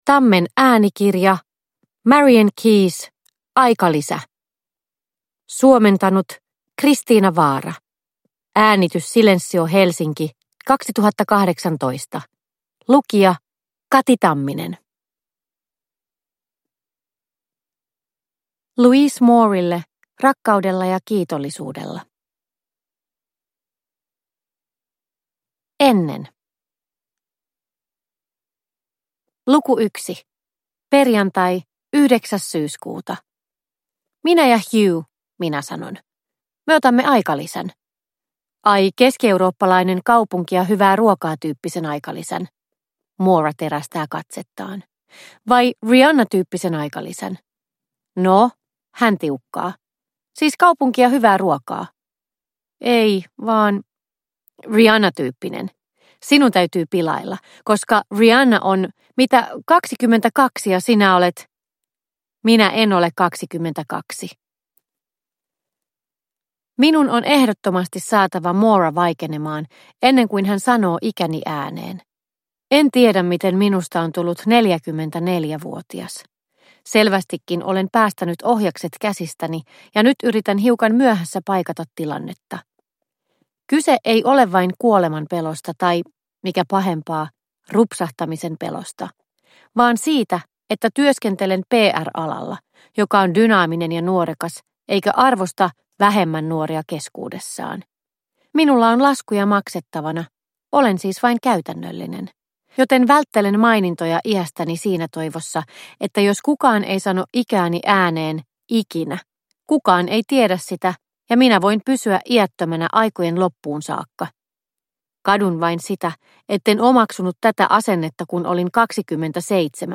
Aikalisä – Ljudbok – Laddas ner